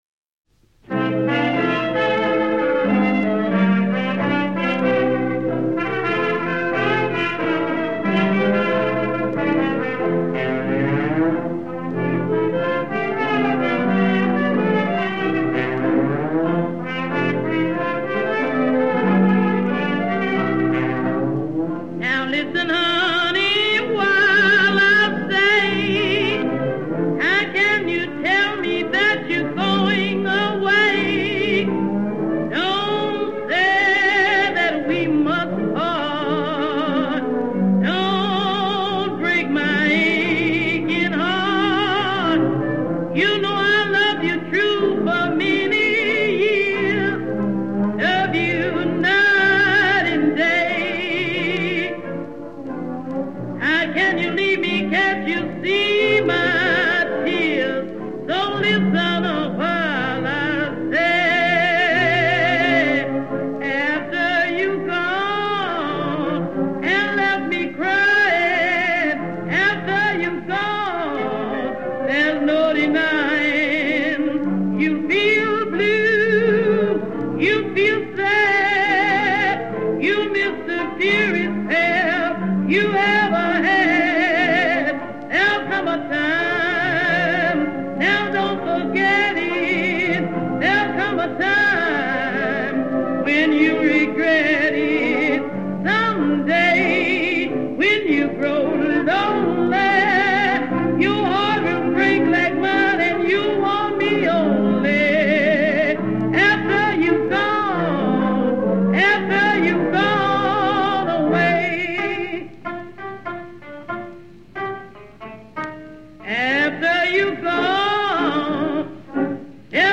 She had power in her voice.